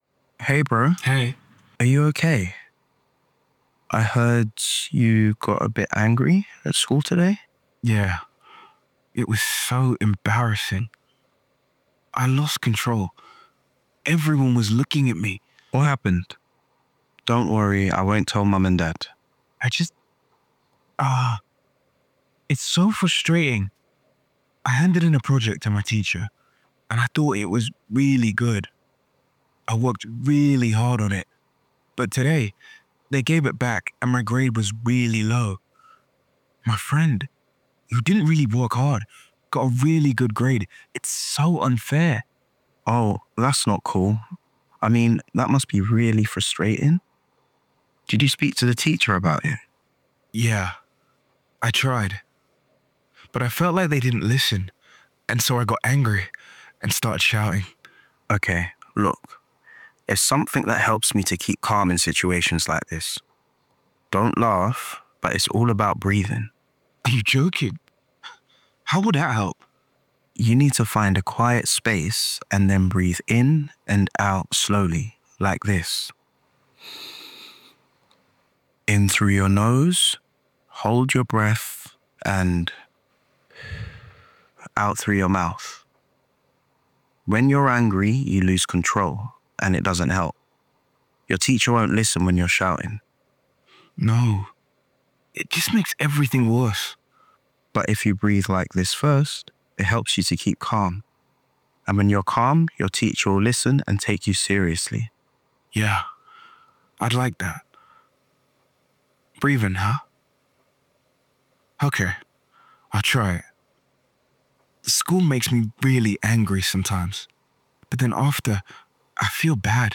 In this free A2 lesson plan for secondary, English learners listen to teenagers talking about managing their emotions.
Lesson outcomes All learners will: explore emotions like anger and frustration practise listening skills with a conversation between an older and younger brother learn some techniques to manage their emotions share their favourite techniques and thoughts in a group discussion.